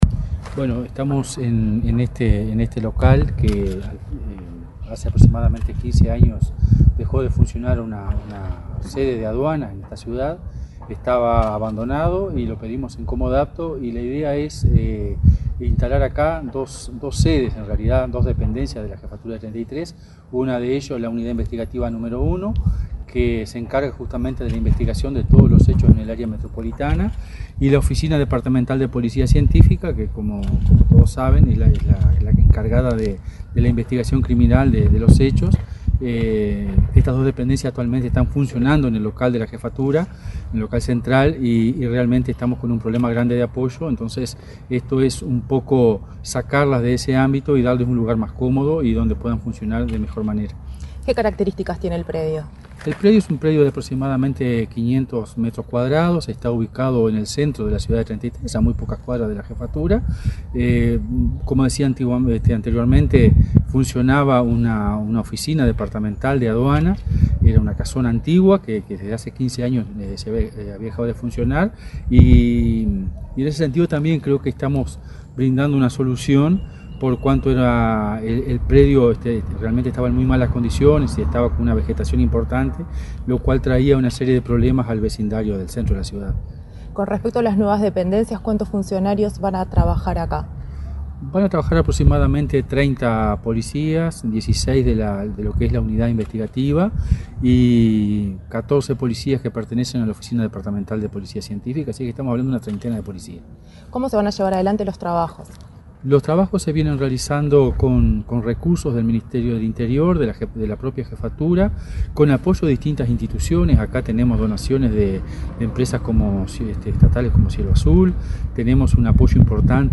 Entrevista al jefe de Policía de Treinta y Tres, Richard Lima